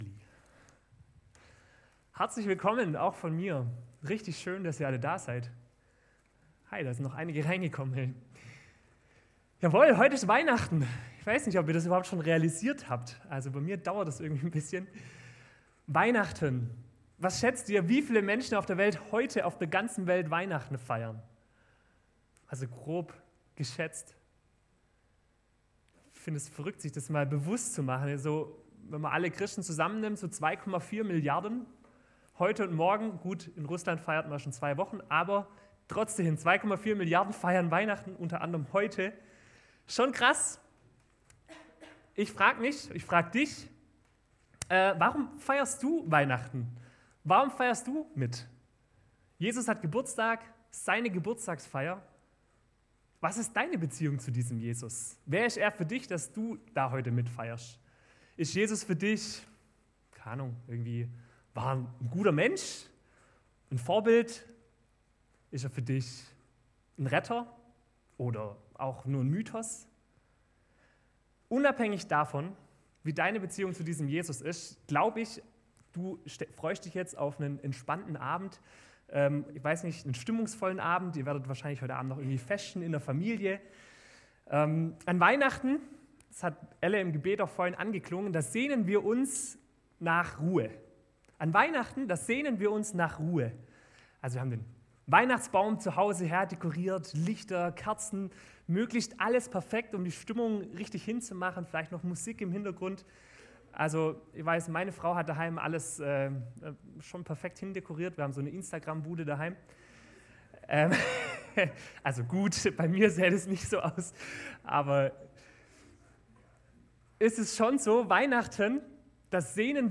Gottestdienst am 24.12.23